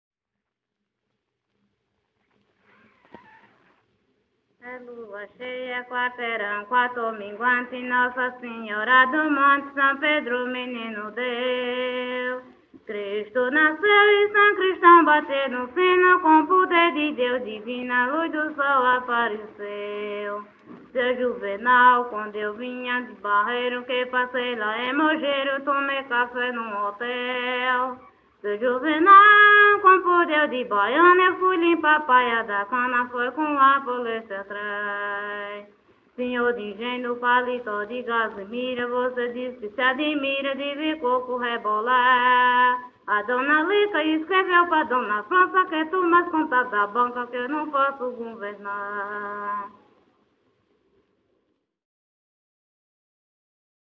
Coco- ""Lua cheia.